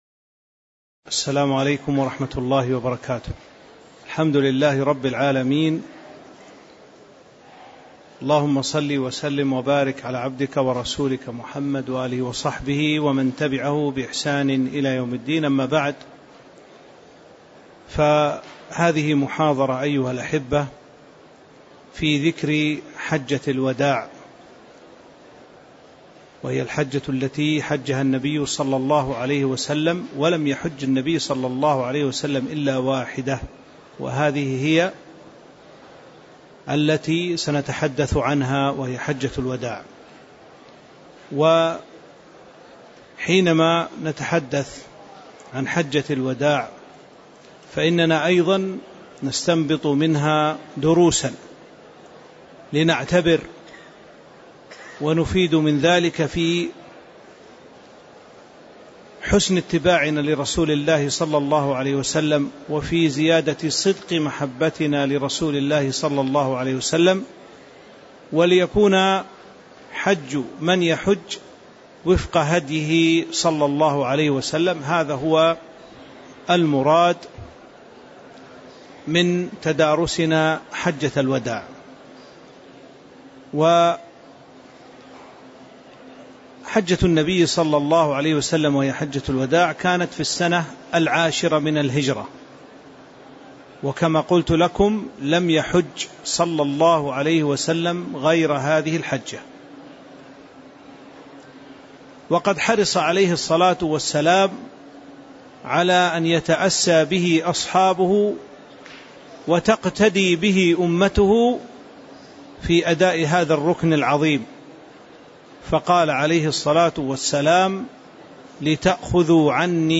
تاريخ النشر ٤ ذو الحجة ١٤٤٤ هـ المكان: المسجد النبوي الشيخ